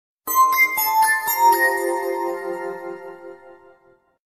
Категория: SMS рингтоны